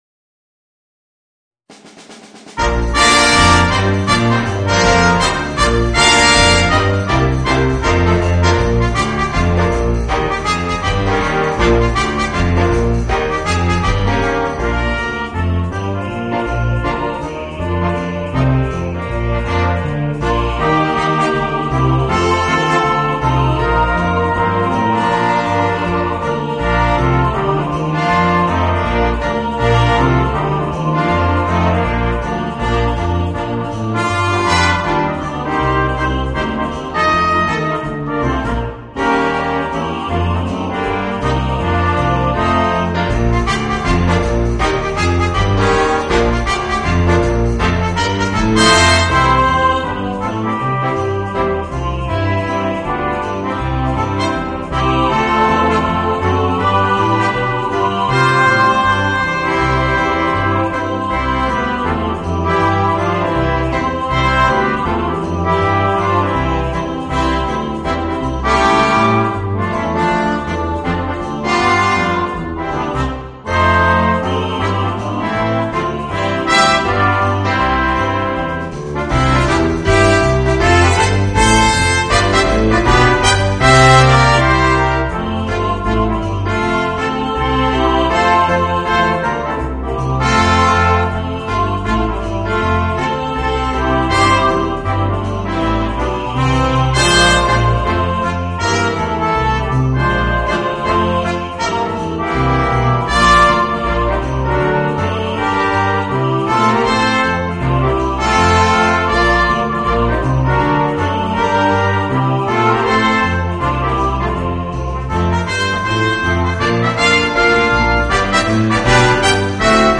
Voicing: 2 Trumpets, Horn, Trombone, Tuba and Chorus